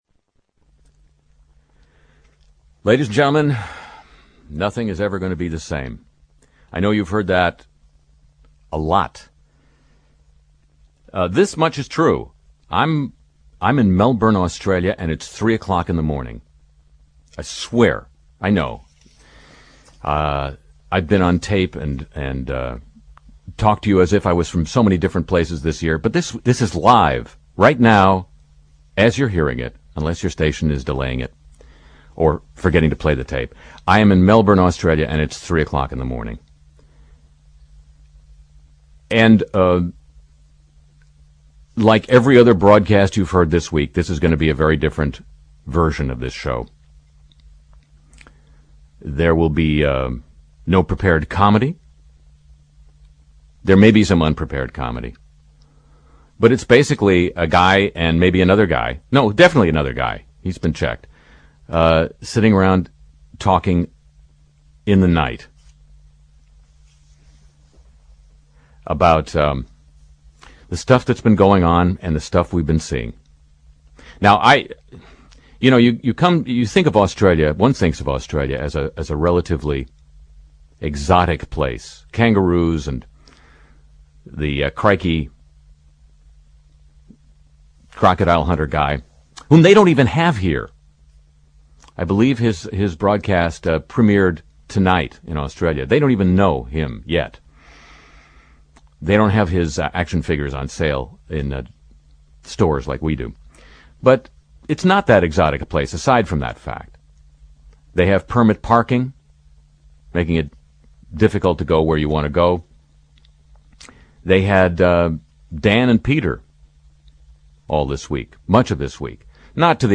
Interview with Santo Cilauro, Australian comic